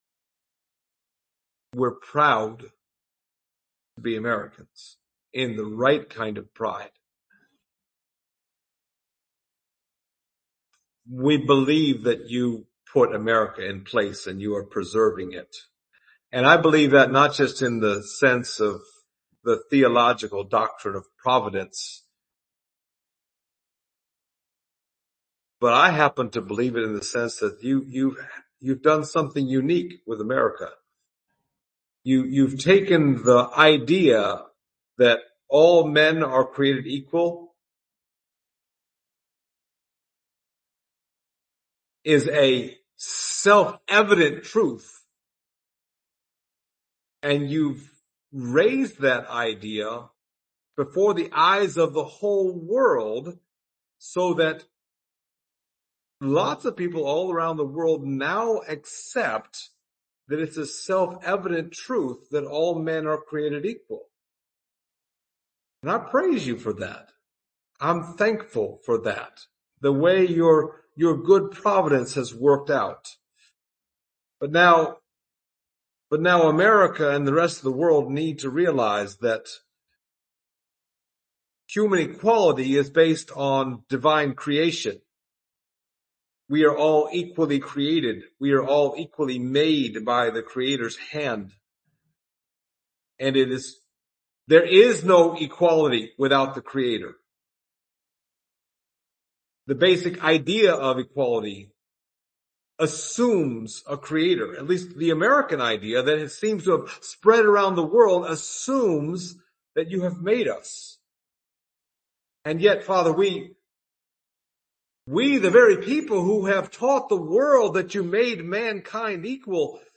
Service Type: Sunday Morning Topics: elders , prophecy , the gospel